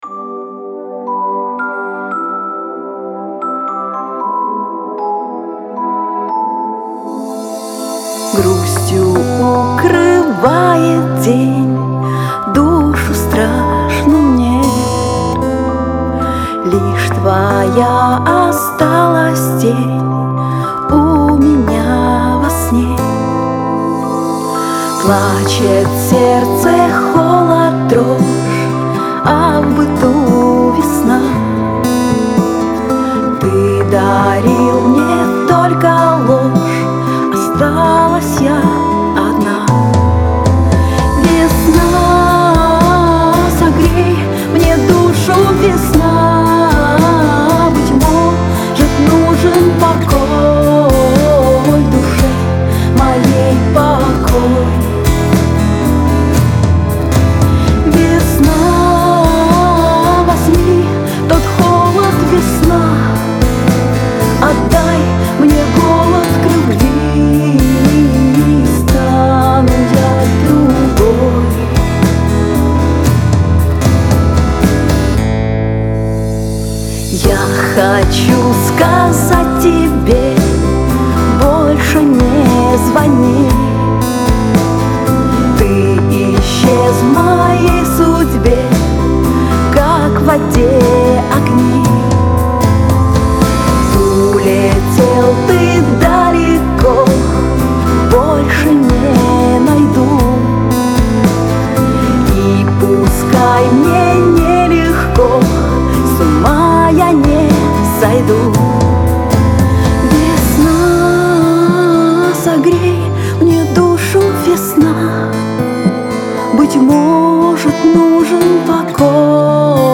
Рубрика: Поезія, Авторська пісня
Красивий голос 23